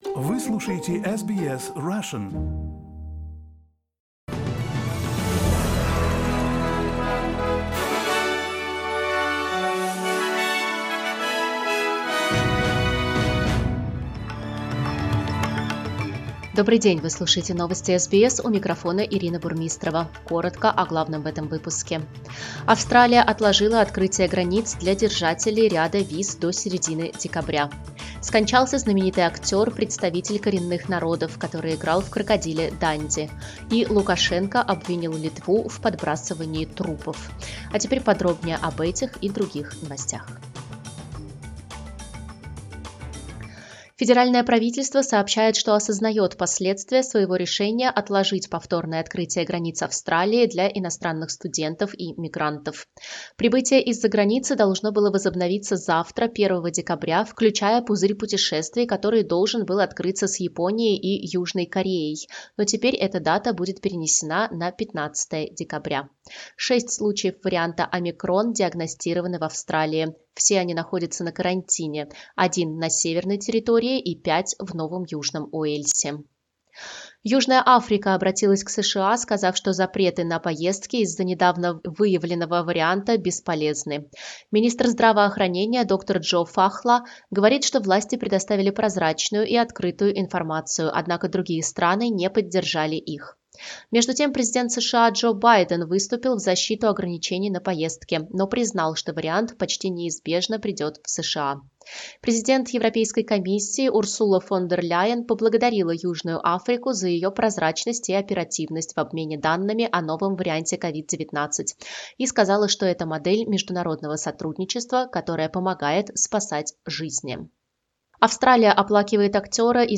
Новости SBS на русском языке - 30.11